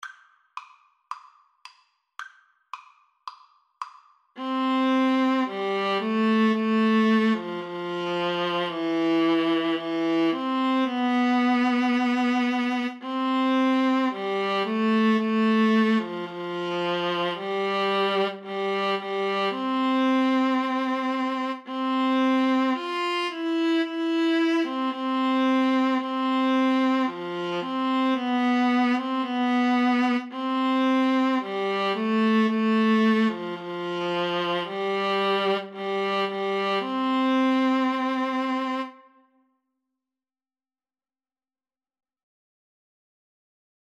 Play (or use space bar on your keyboard) Pause Music Playalong - Player 1 Accompaniment reset tempo print settings full screen
C major (Sounding Pitch) (View more C major Music for Viola Duet )
Viola Duet  (View more Easy Viola Duet Music)
Classical (View more Classical Viola Duet Music)